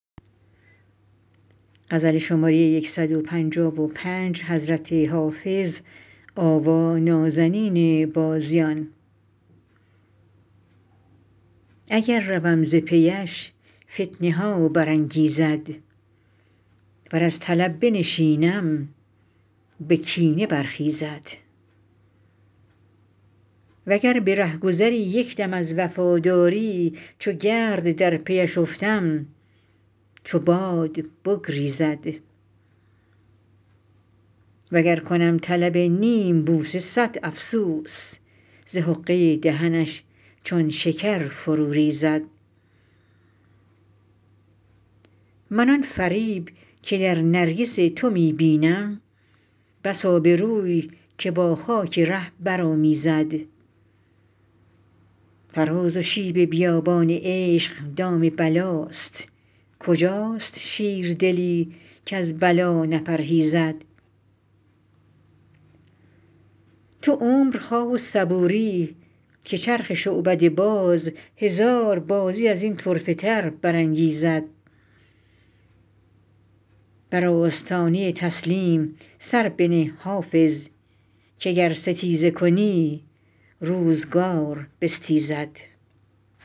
حافظ غزلیات غزل شمارهٔ ۱۵۵ به خوانش